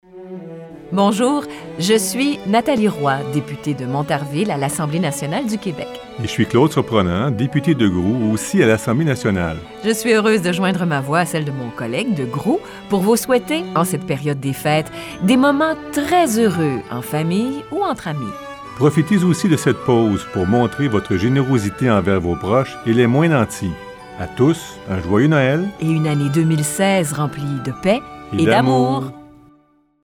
Enregistrement des Voeux de Noël à Radio-Classique en compagnie de mon collègue Claude Surprenant, député de Groulx.